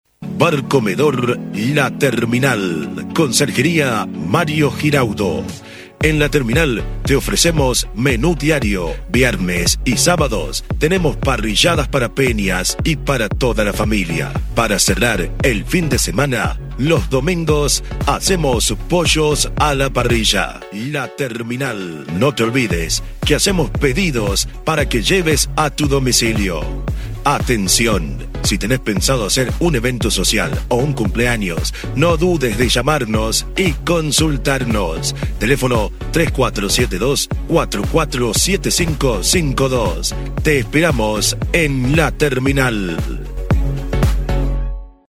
Una voz ideal para todo tipo de comerciales con una acento latino